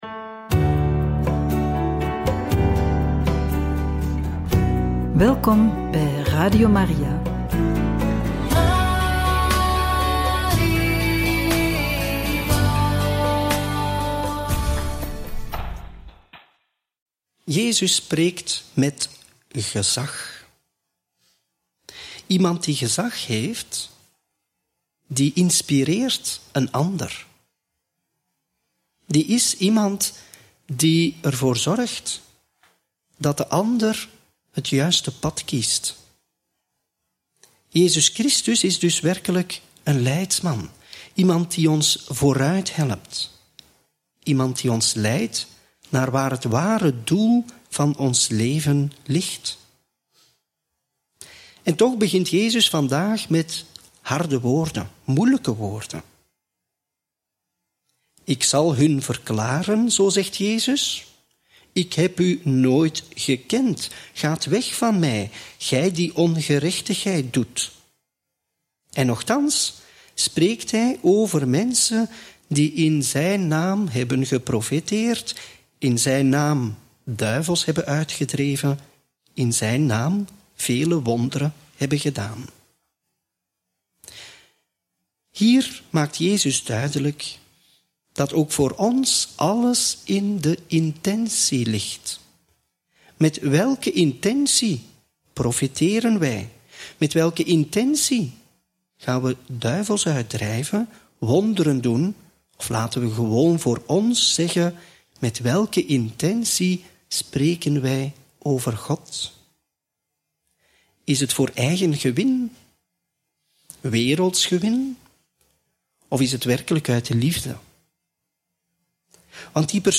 Homilie bij het Evangelie op donderdag 26 juni 2024 (Mt. 7, 21-29)